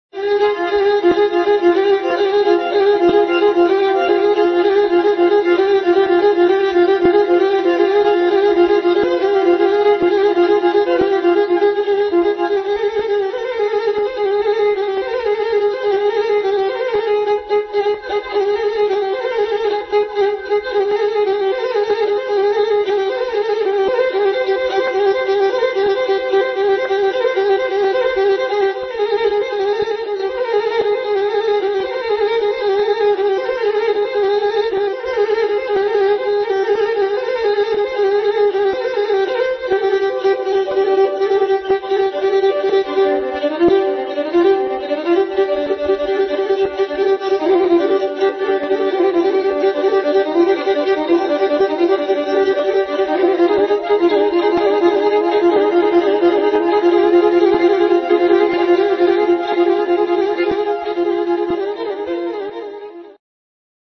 The Pontic version of the ancient "pyrrhic dance" it starts in 7/4 and accelerates to 7/16.